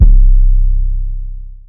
kits/OZ/808s/808 (Subaru).wav at ts
808 (Subaru).wav